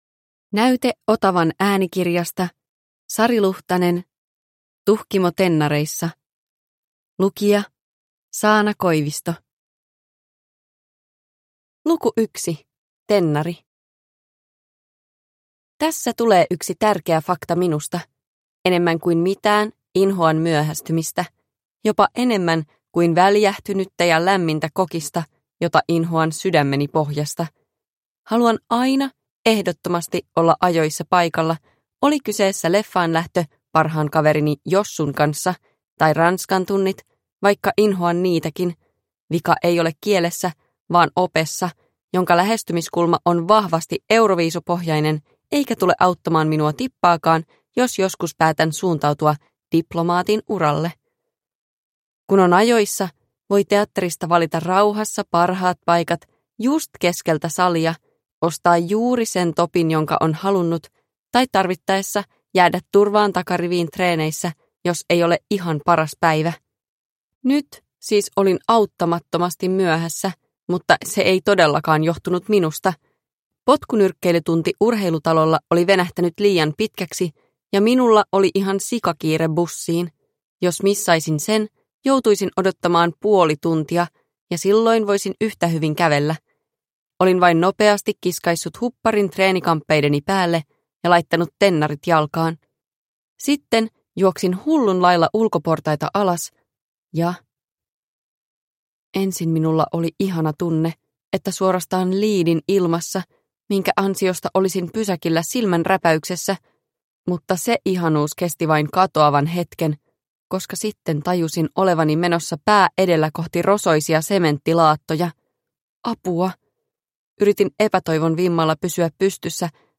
Tuhkimo tennareissa – Ljudbok – Laddas ner